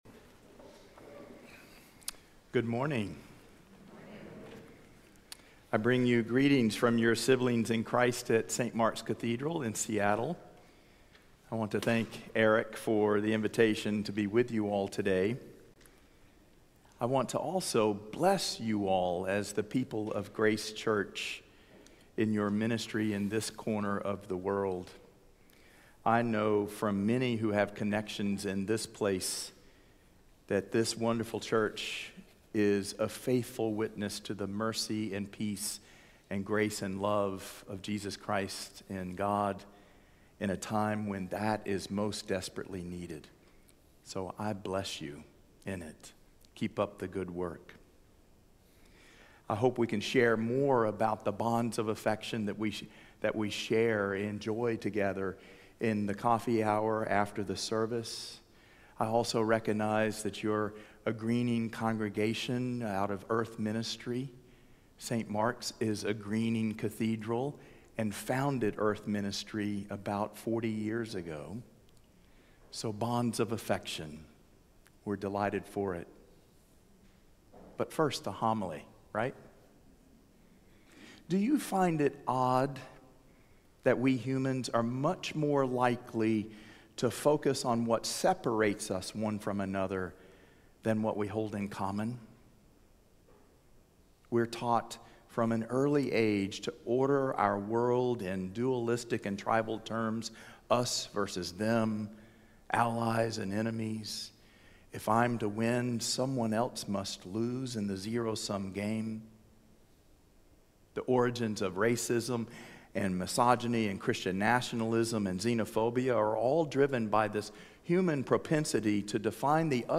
Sermons | Grace Episcopal Church